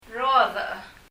発音： 最後にきちっと ch の音が入ってます、 「ため息出しゃいいのかな？」とか思ったり・・そんな風にも聞こえますが・・
« song 歌 banana バナナ » fruit 果物 rodech [rɔ:ðə ! ] 発音： 最後にきちっと ch の音が入ってます、 「ため息出しゃいいのかな？」